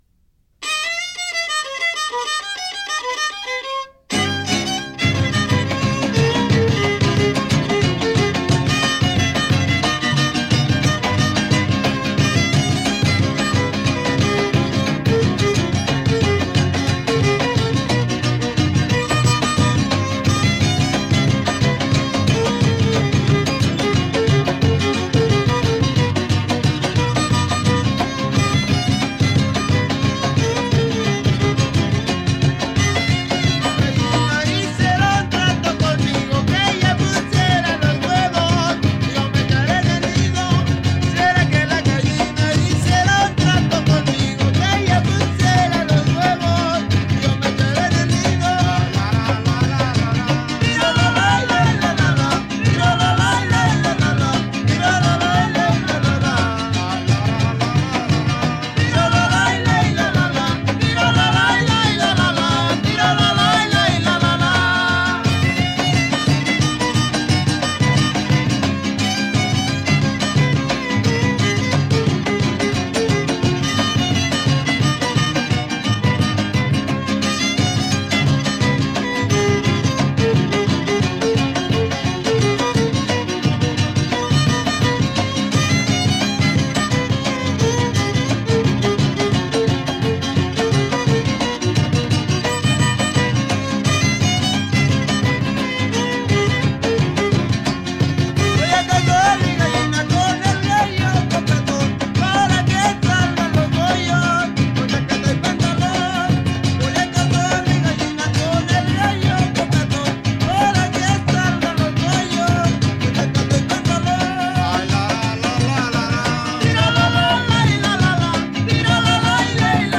Keywords: arpa grande
región occidente de México
folklor mexicano
Grabaciones de campo